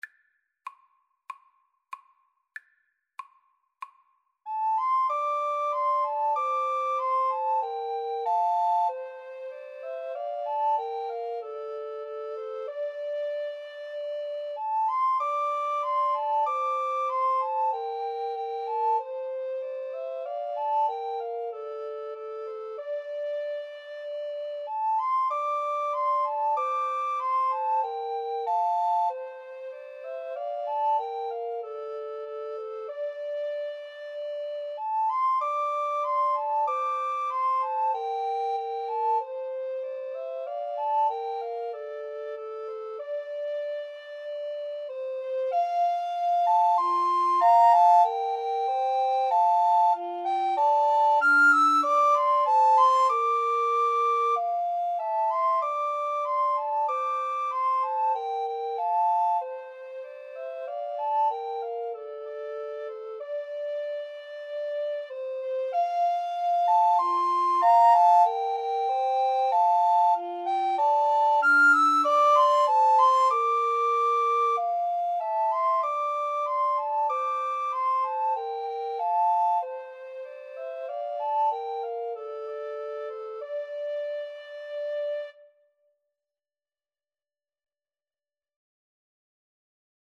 Free Sheet music for Recorder Trio
= 95 Moderato
D minor (Sounding Pitch) (View more D minor Music for Recorder Trio )
Traditional (View more Traditional Recorder Trio Music)